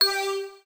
UIClick_Mallet Tonal Long 02.wav